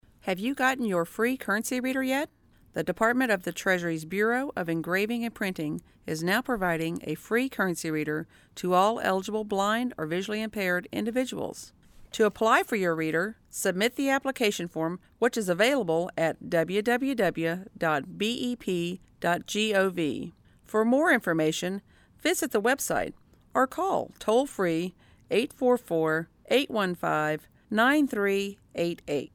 :30 iBill Public Service Announcement